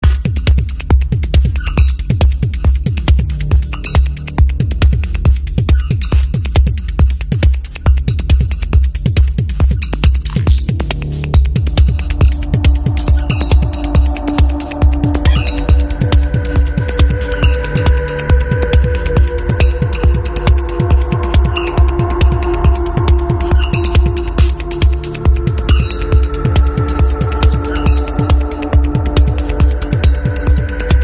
esplorazioni elettroniche minimaliste e dettagliate
Electronic , Experimental , Techno